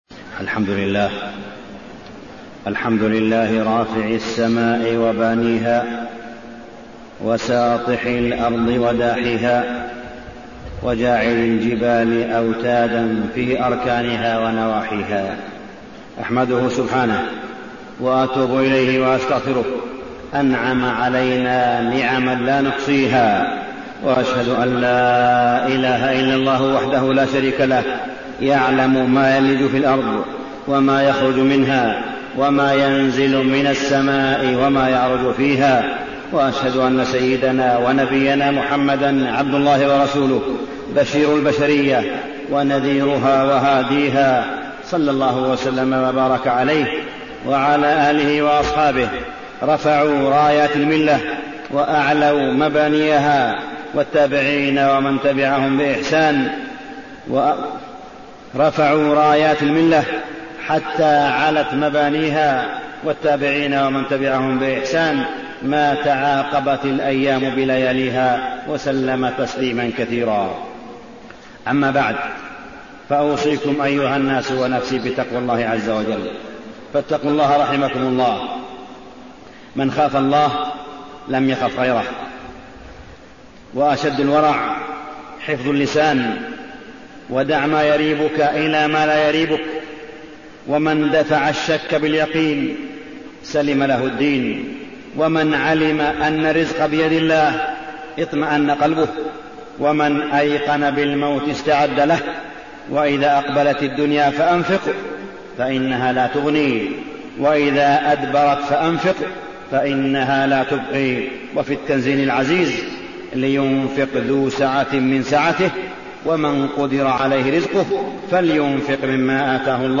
تاريخ النشر ٢٥ ذو القعدة ١٤٣٠ هـ المكان: المسجد الحرام الشيخ: معالي الشيخ أ.د. صالح بن عبدالله بن حميد معالي الشيخ أ.د. صالح بن عبدالله بن حميد رسالة لحجاج بيت الله الحرام